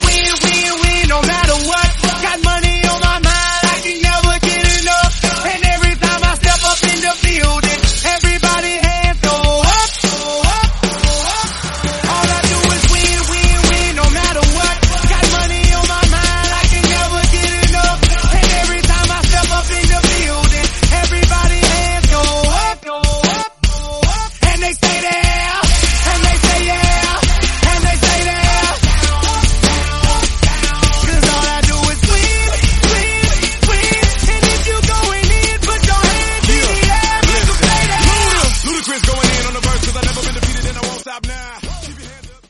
Genre: 70's
Clean BPM: 120 Time